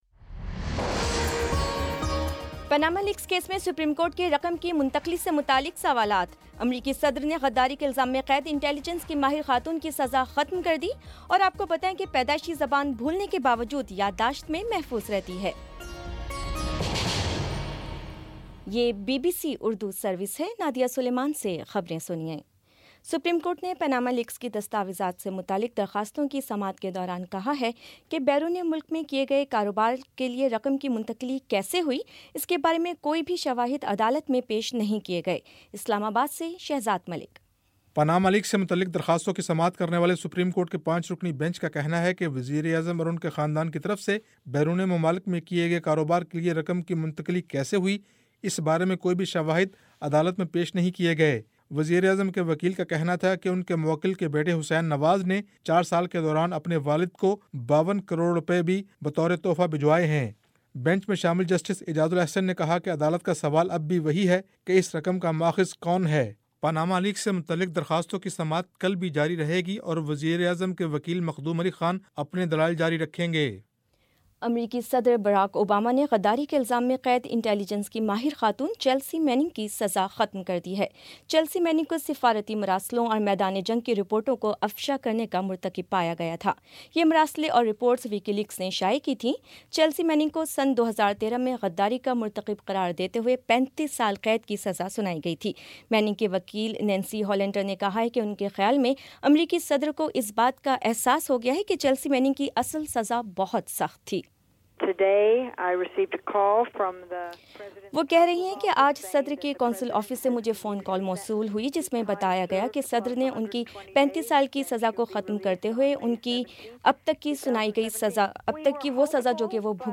جنوری 18 : شام پانچ بجے کا نیوز بُلیٹن